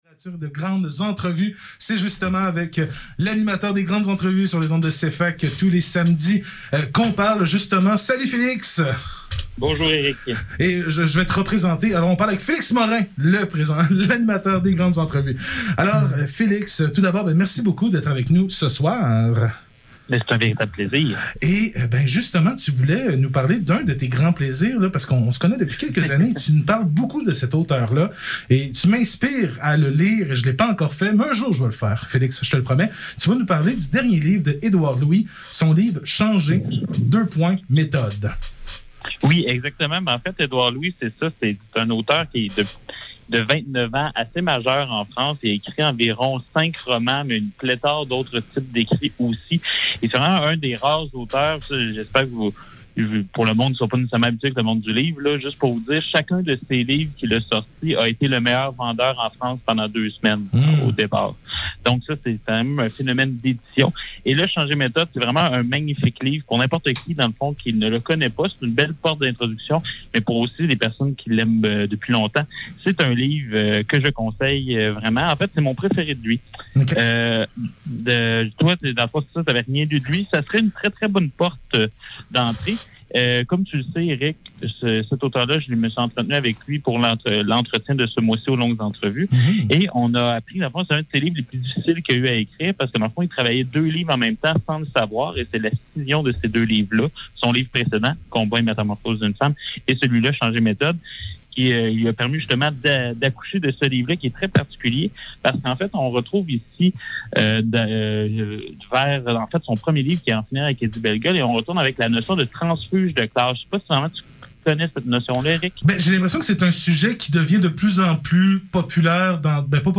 Chronique littéraire